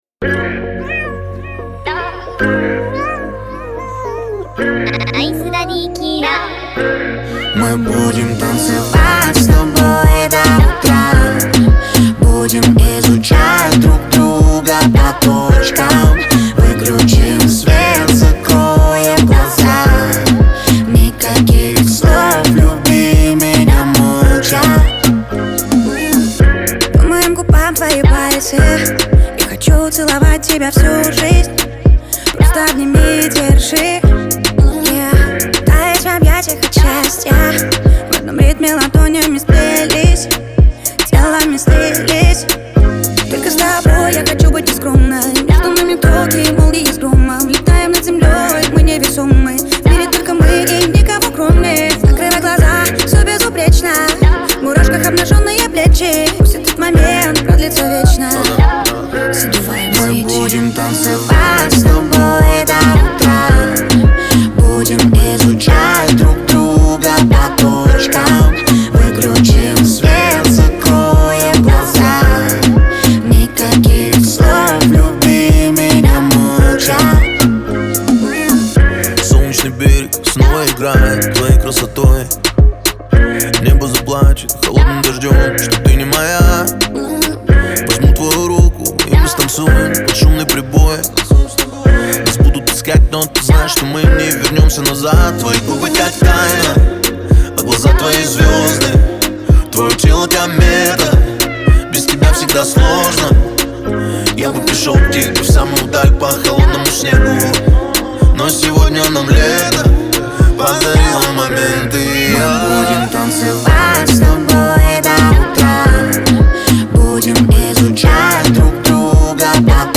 это яркий пример современного поп-музыки с элементами EDM.
мелодичные вокалы